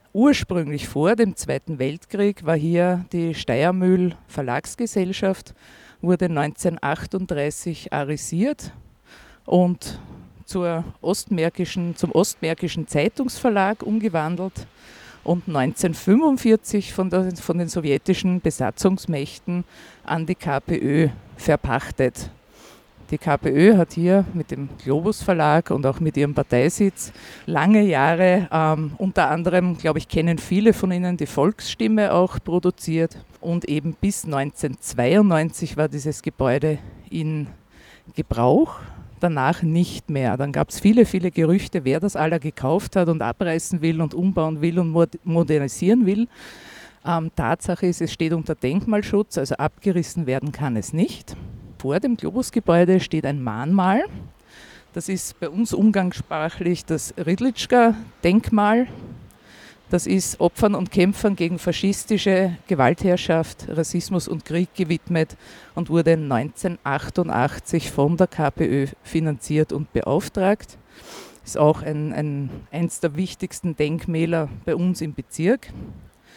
Christine Dubravac-Widholm, Bezirksvorsteher-Stellvertreterin führte am 31. August das Geh-Café durch ihren Bezirk.
globusverlag-geh-cafe-brigittenau-2022.mp3